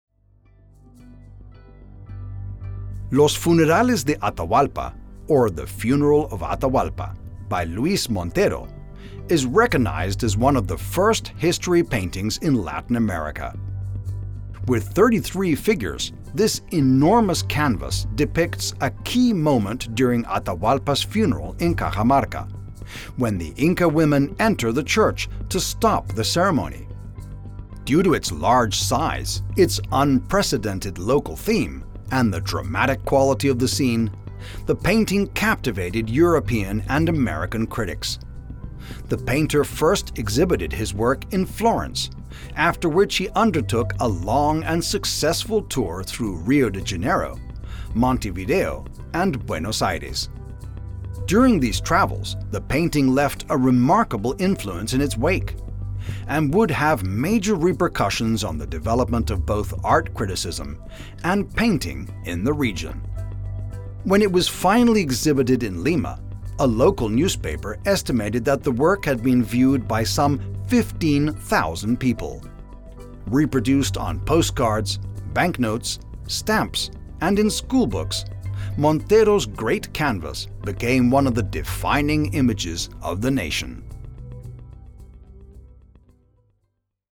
AUDIO GUIDE (ENGLISH)